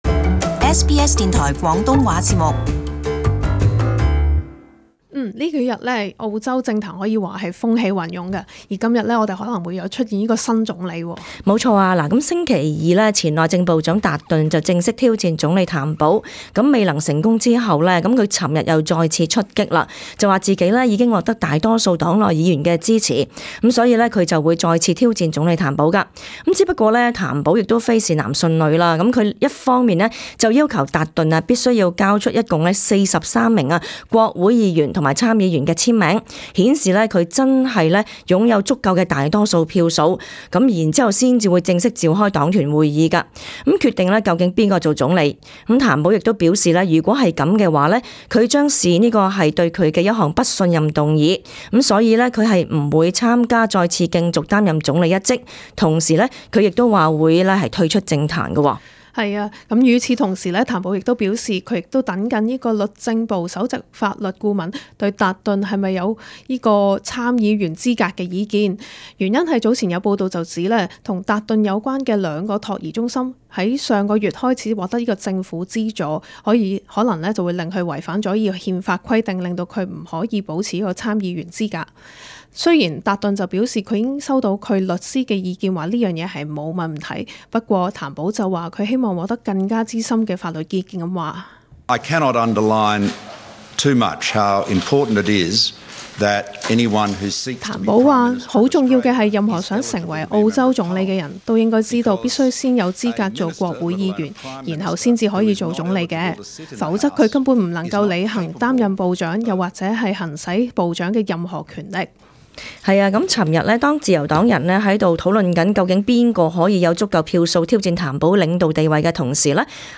【時事報導】43 名自由黨議員簽名召開黨團會議， 可能選出新總理。